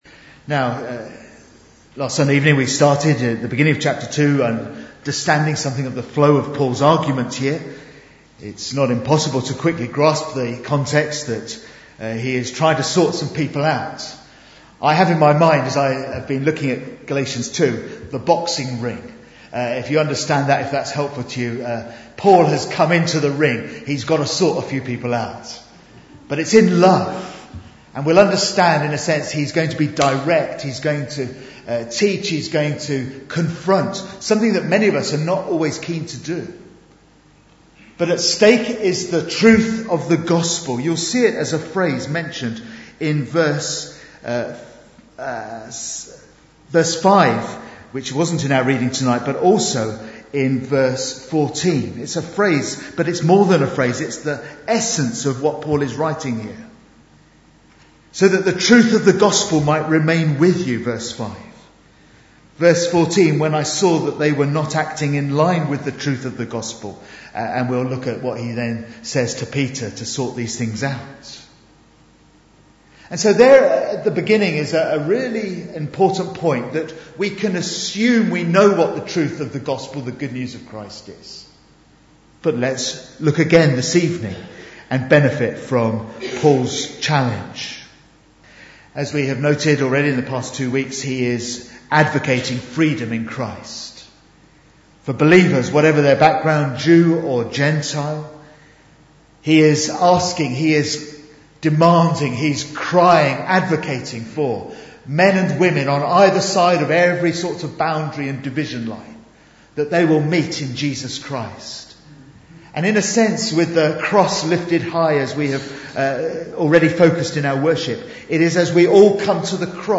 Bible Text: Galatians 2:11-21 | Preacher